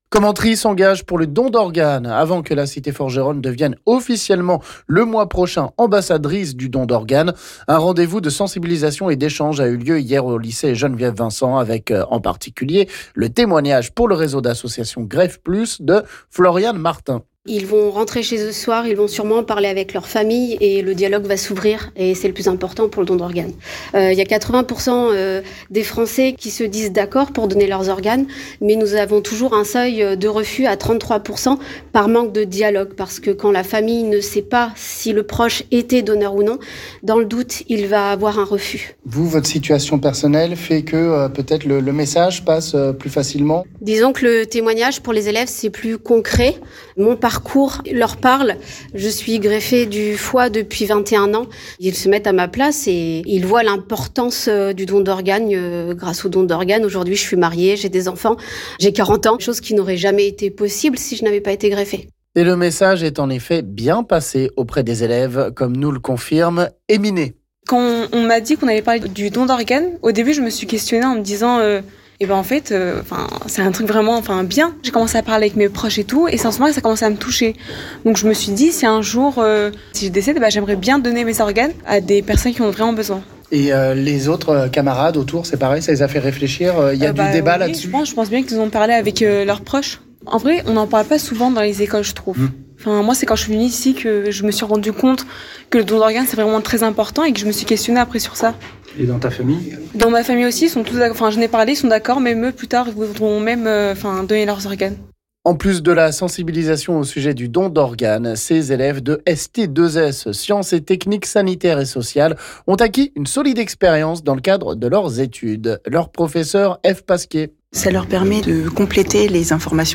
Le but étant de mettre encore plus en avant ce sujet, et de sensibiliser davantage, comme hier au lycée Geneviève Vincent avec le témoignage d’une femme greffée du foie.